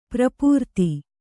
♪ prapūrti